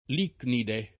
[ l & knide ]